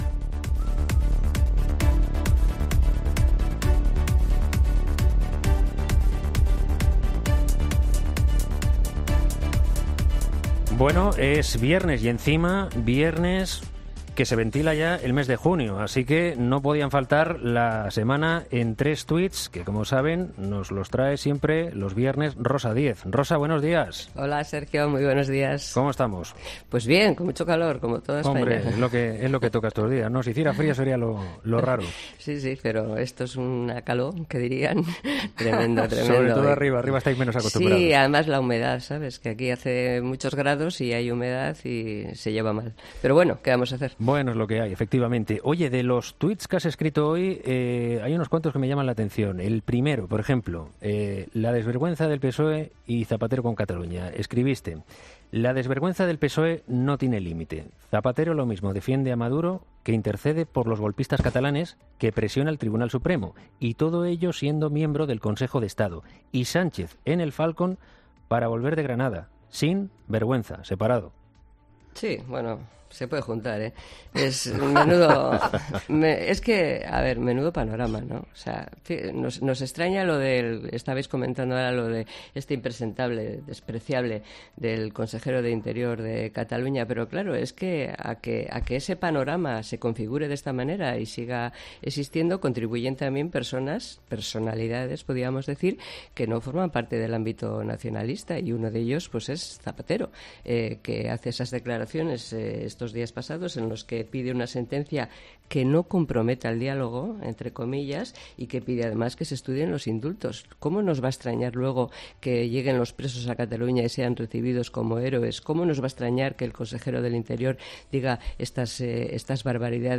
Los 'gestos' del PSOE con Bildu y la entrevista a Otegi en TVE han centrado la semana política para Rosa Díez. En su sección de los viernes en 'Herrera en COPE', la exlíder de UPyD ha cargado duramente contra el PSOE por sus guiños constantes a Bildu.